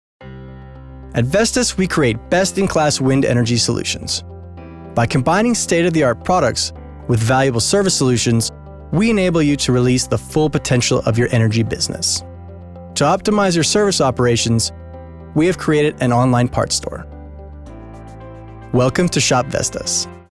Engelsk (US)
Mand
30-50 år